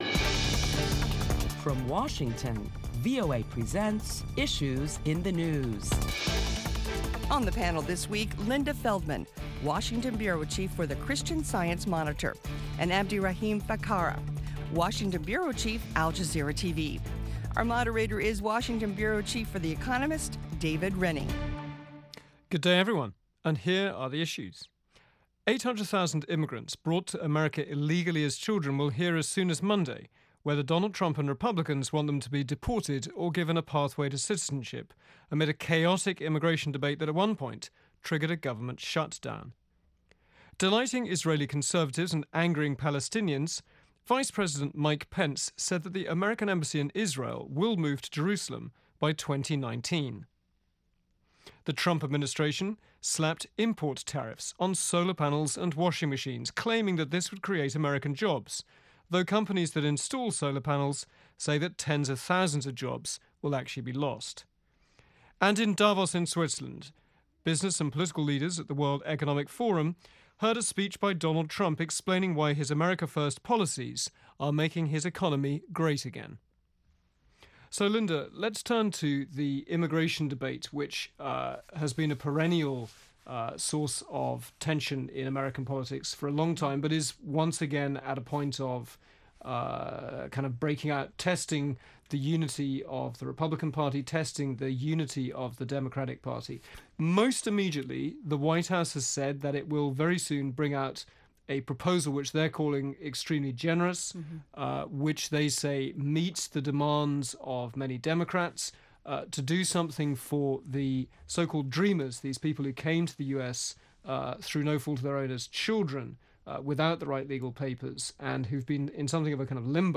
This week on Issues in the News, top Washington journalists talk about the week's headlines including President Trump’s trip to Davos, Switzerland for the World Economic Forum.